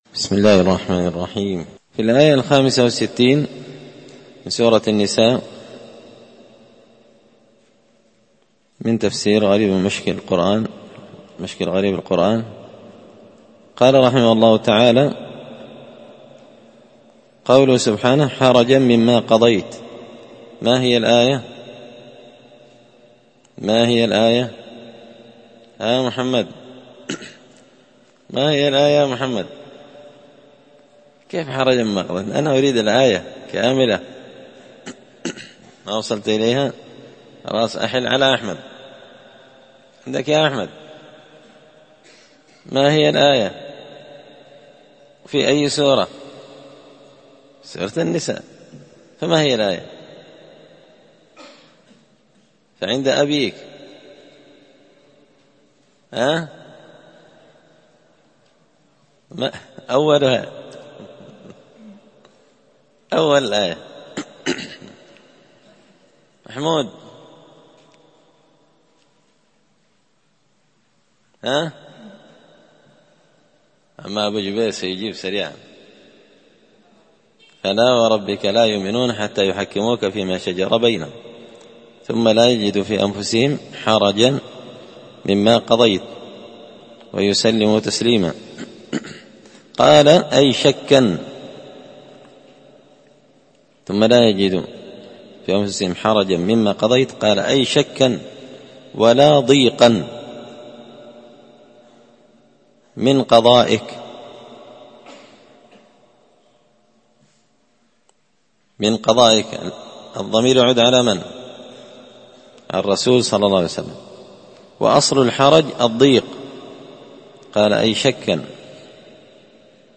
تفسير المشكل من غريب القرآن ـ الدرس 95
مسجد الفرقان_قشن_المهرة_اليمن